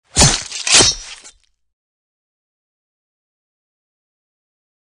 knife.ogg